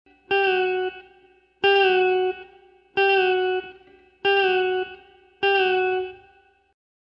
The fourth bend is a prebend of 1/2 step on the second string 7th fret, with a release.
This gives an interesting sound: a note which starts high and bends down as opposed to a normal bend where the note starts low and bends up.